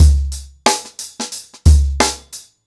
TheStakeHouse-90BPM.29.wav